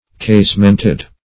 Casemented \Case"ment*ed\, a. Having a casement or casements.